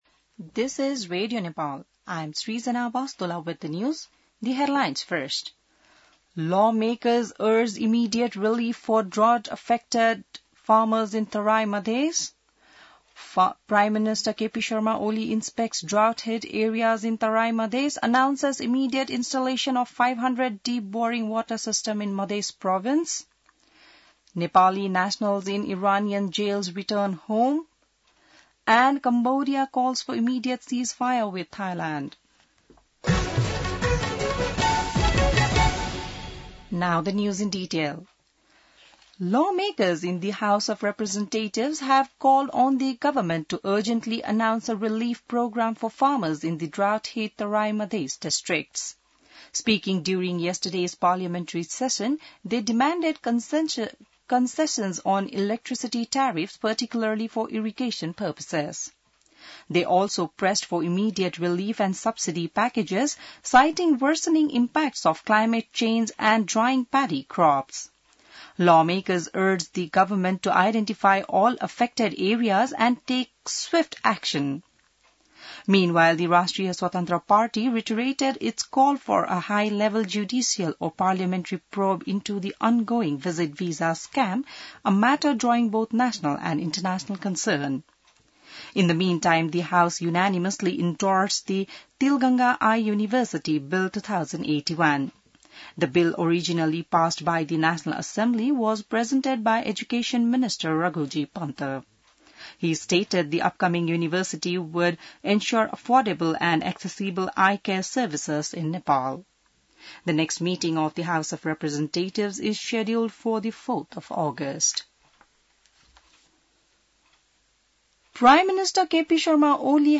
बिहान ८ बजेको अङ्ग्रेजी समाचार : १० साउन , २०८२